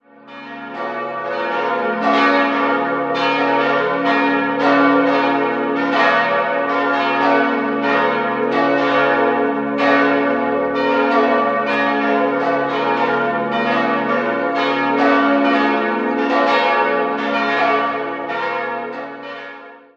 4-stimmiges Salve-Regina-Geläute: des'-f'-as'-b'